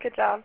Seven prosodic variants of good job (au files):
elongated
elongated.au